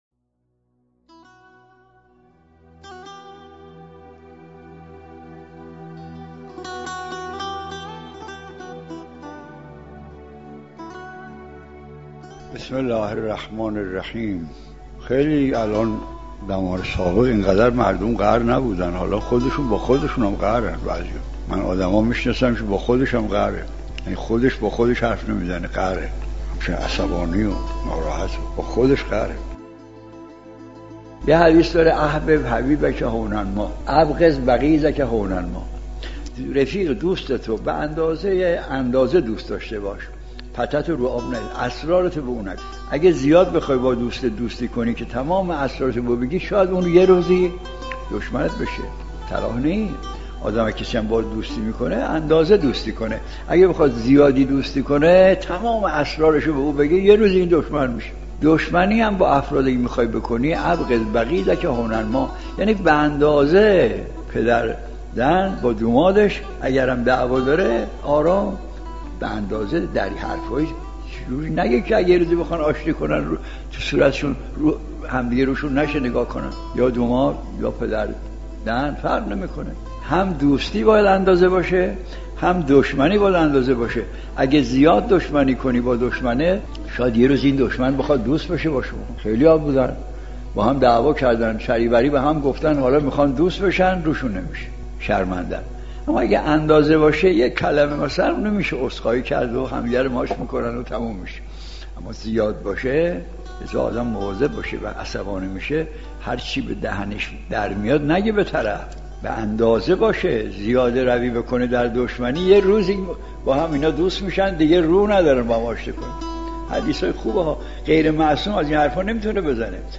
صحبت های مرحوم آیت الله مجتهدی تهرانی(ره) درباره قهر با دیگران.